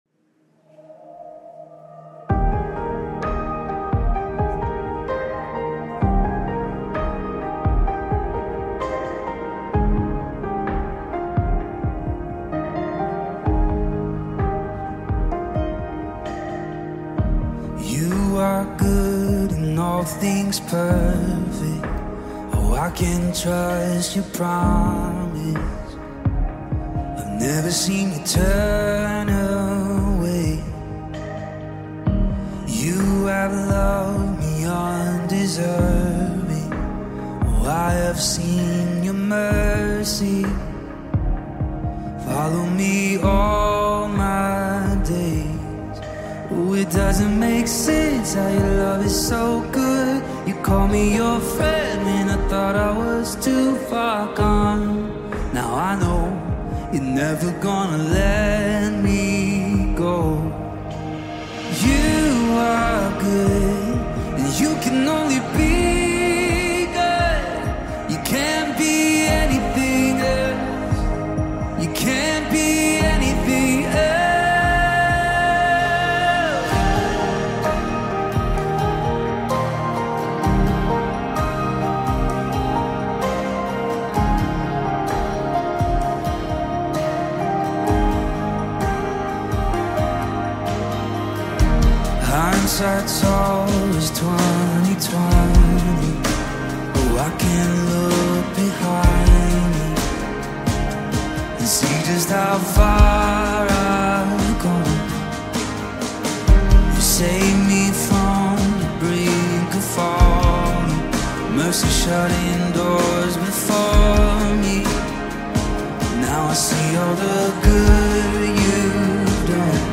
The official live video can be viewed below.